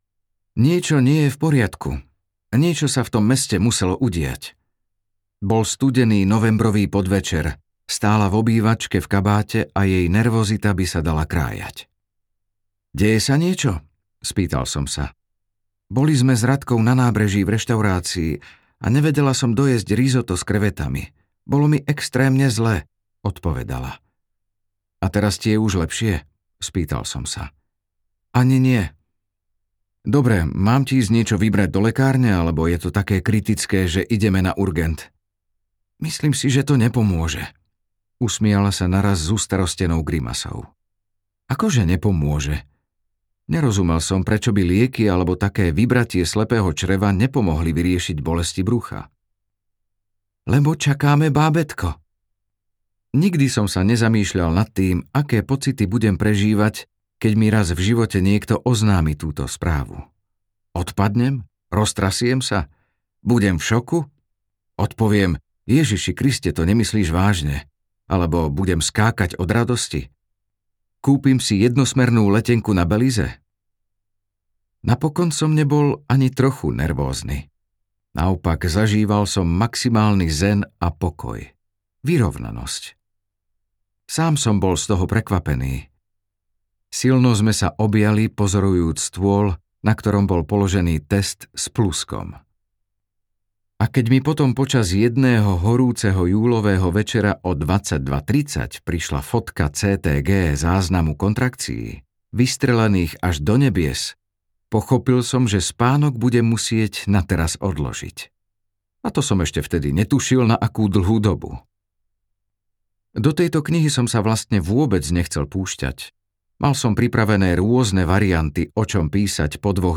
Ukázka z knihy
protokol-prirodzenej-plodnosti-a-sexualneho-apetitu-audiokniha